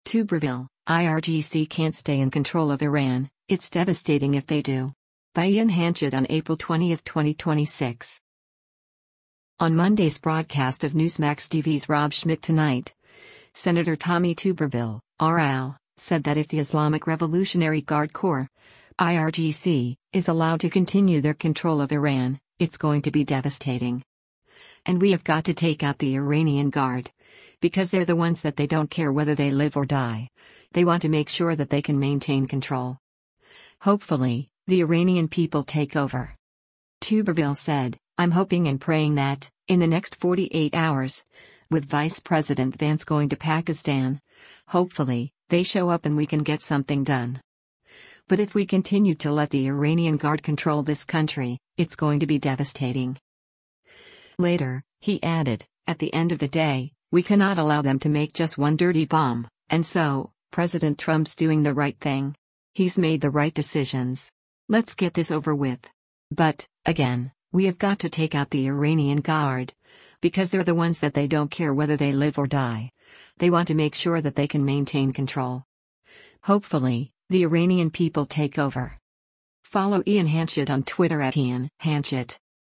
On Monday’s broadcast of Newsmax TV’s “Rob Schmitt Tonight,” Sen. Tommy Tuberville (R-AL) said that if the Islamic Revolutionary Guard Corps (IRGC) is allowed to “continue” their control of Iran, “it’s going to be devastating.”